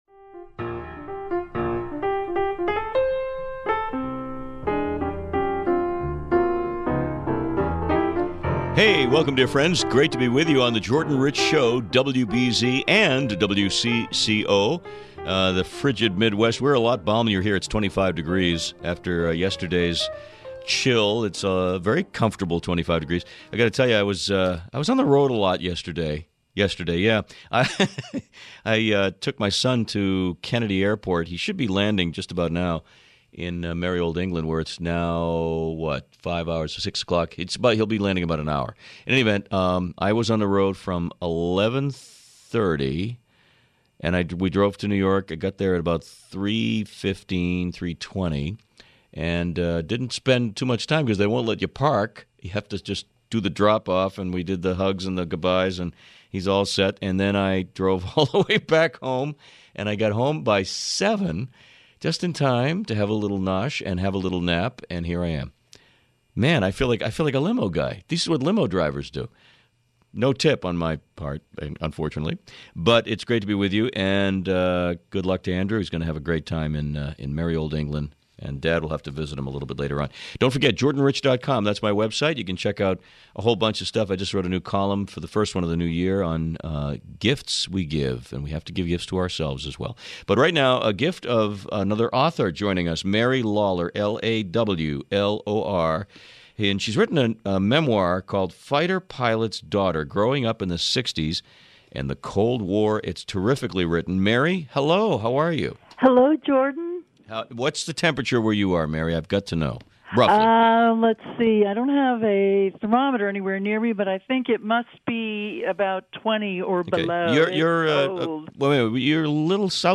Interview on WBZ Boston